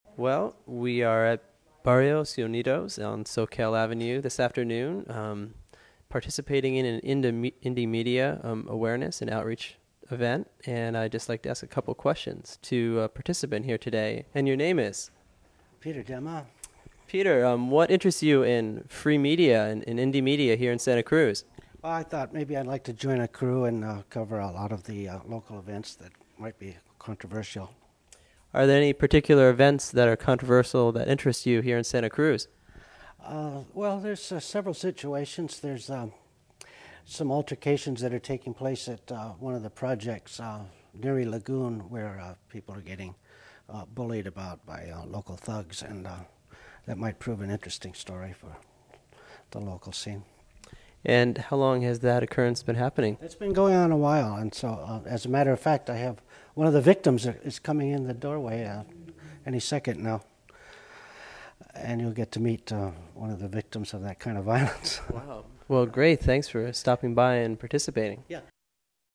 LOCAL Interview :: Alternative Media : Resistance & Tactics